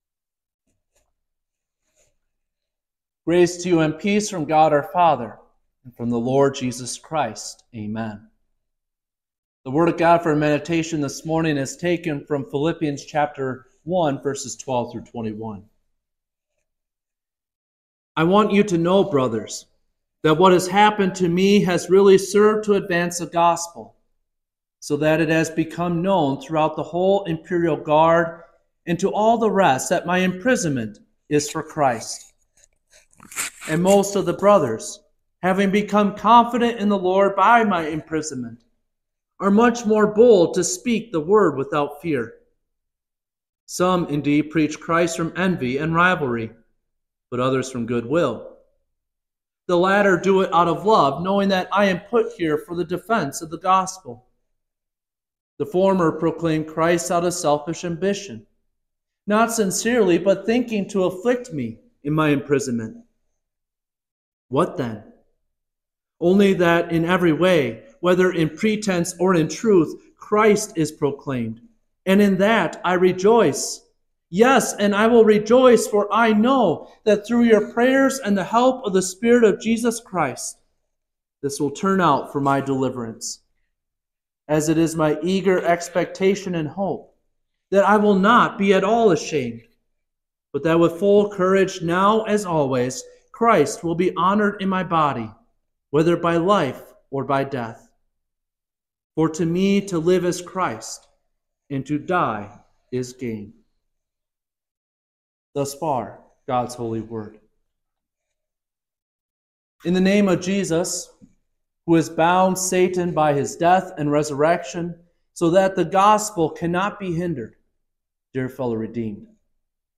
Sexagesima-Sunday.mp3